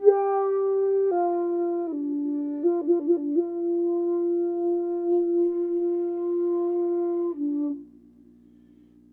Flute2_105_D.wav